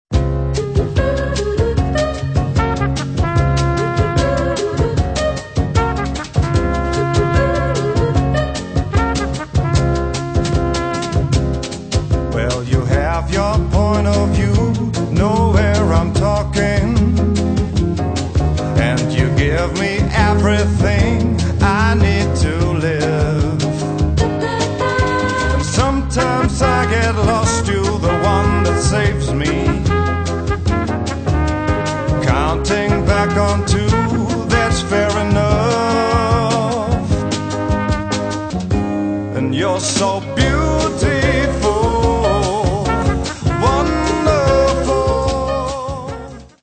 Das klingt doch nach tiefsten Siebziger Jahren!